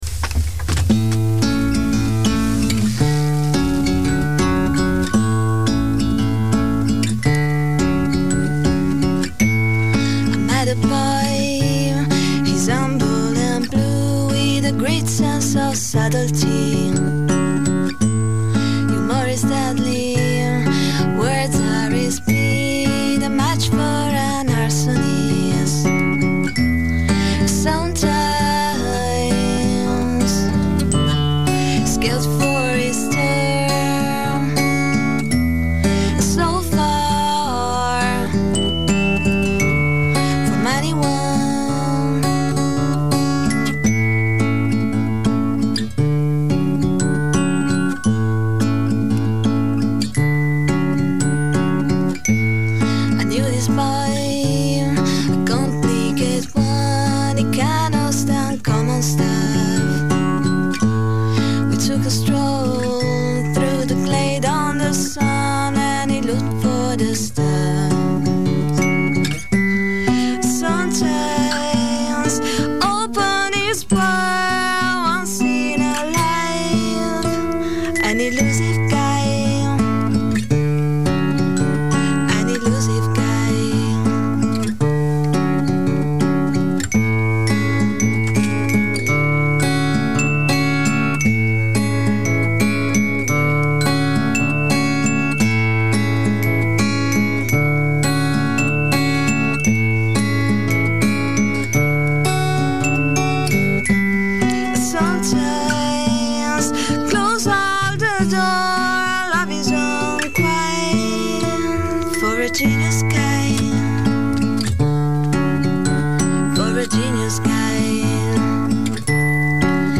ci ha regalato due canzoni dal vivo: